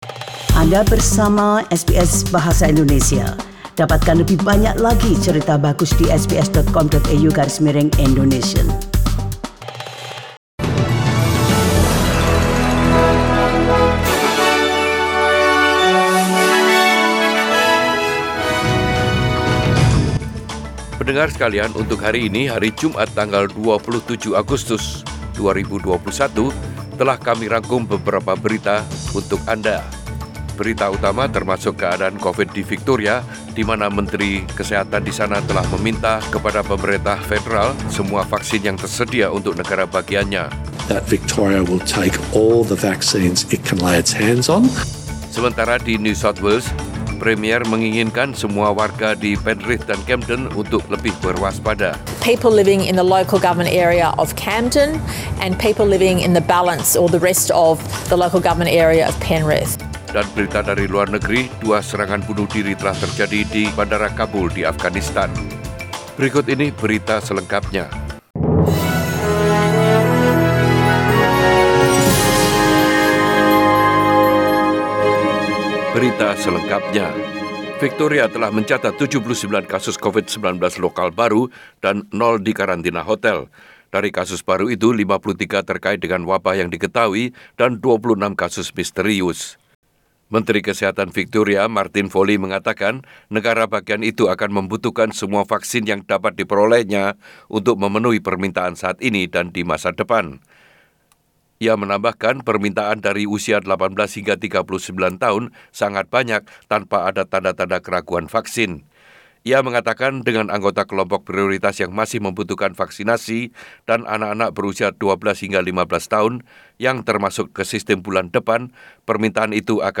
SBS Radio News in Bahasa Indonesia - 27 August 2021
Warta Berita Radio SBS Program Bahasa Indonesia.